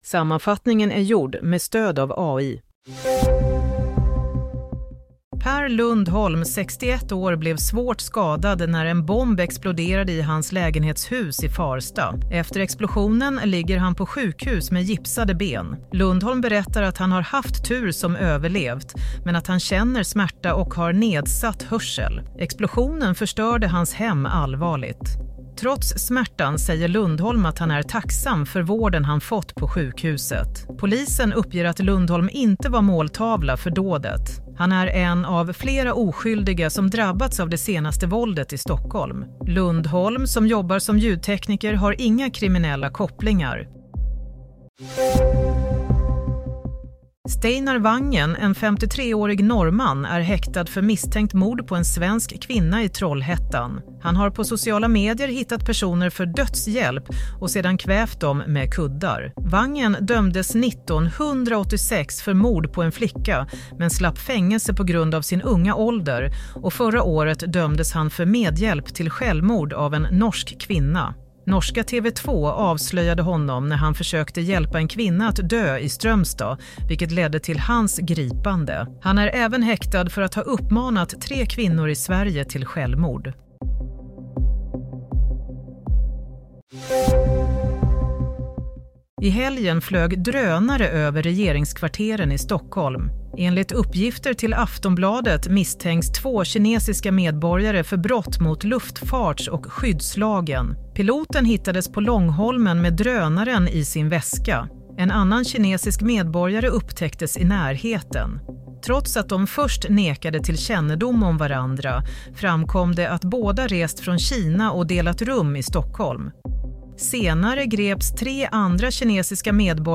Nyhetssammanfattning - 29 januari 07:00
Sammanfattningen av följande nyheter är gjord med stöd av AI.